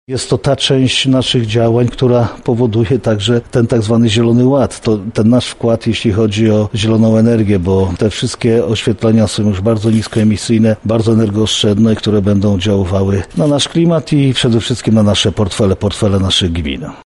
-mówi Marszałek Województwa Lubelskiego Jarosław Stawiarski.